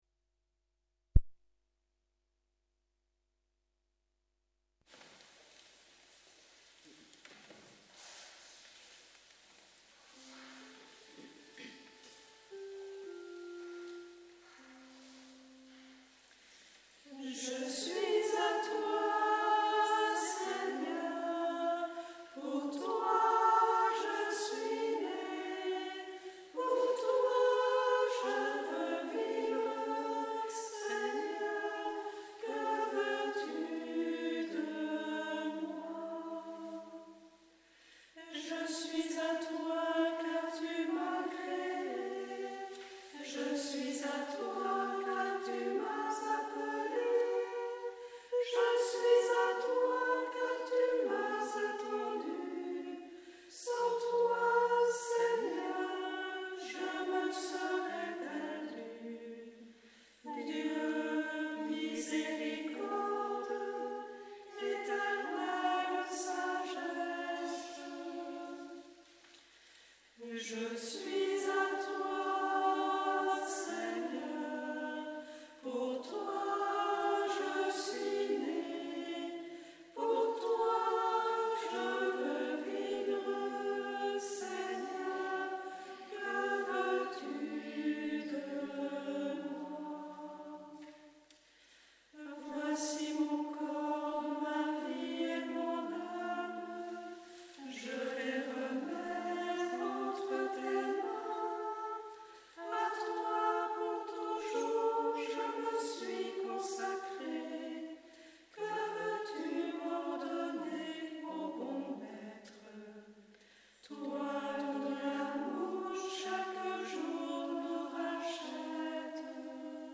Pour écouter la célébration cliquez ici